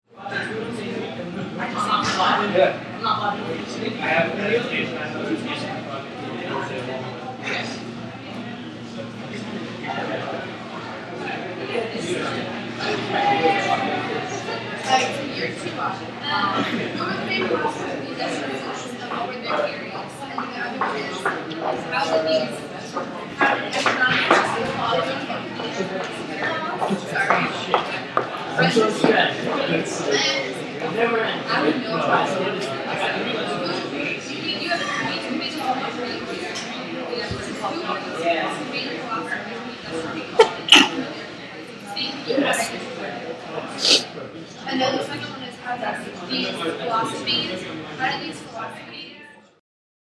Location: Entrance to the Axinn Library
Sounds Heard: talking, shoes clicking, sneeze
Library.mp3